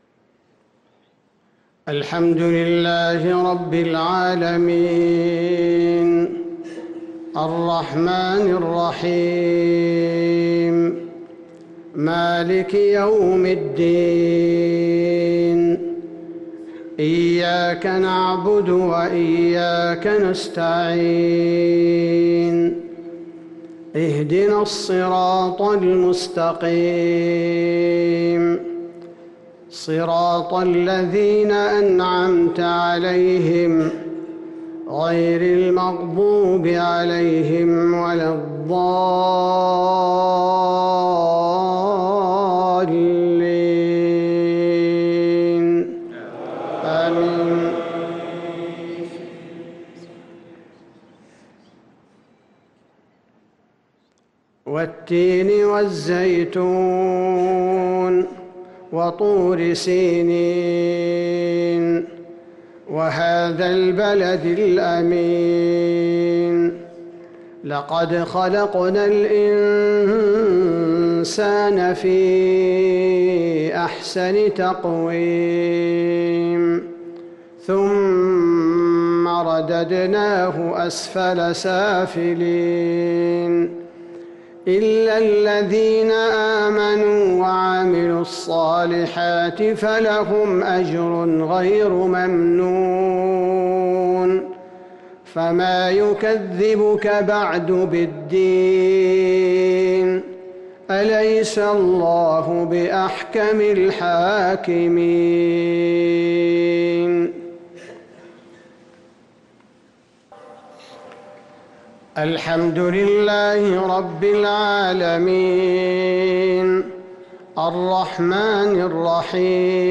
صلاة المغرب للقارئ عبدالباري الثبيتي 15 ذو القعدة 1444 هـ
تِلَاوَات الْحَرَمَيْن .